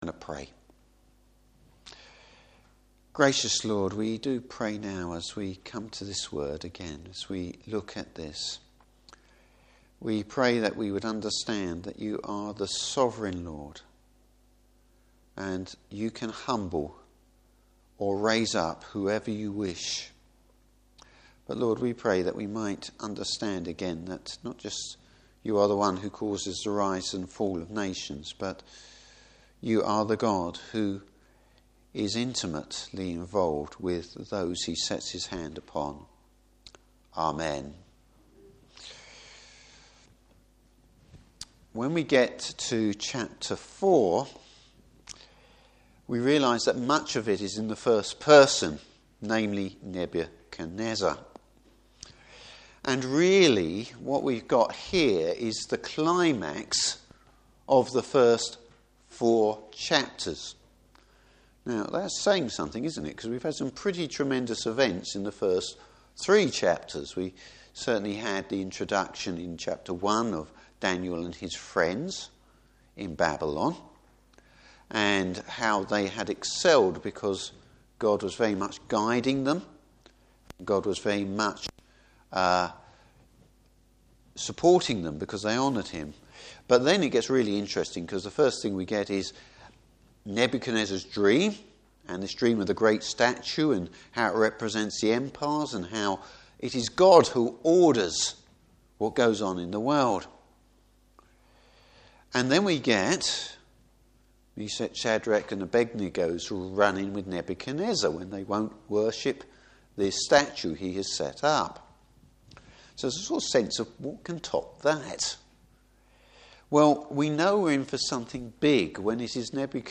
Service Type: Evening Service The reality of living in God’s world!